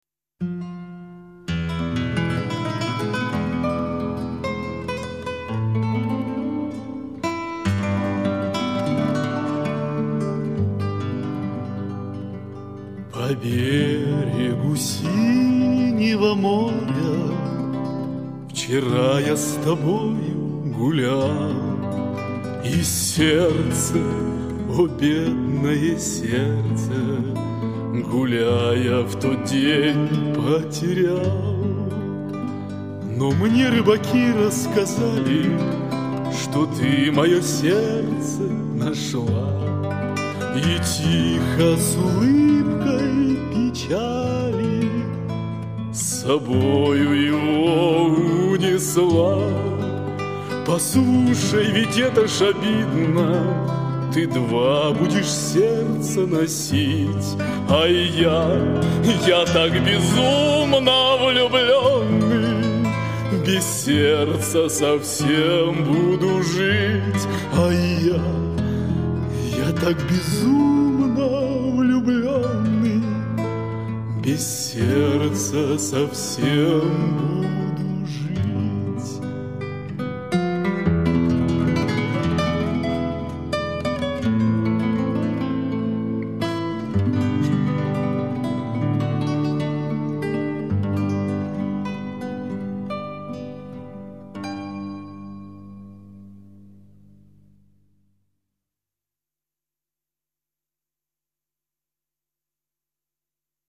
гитара, вокал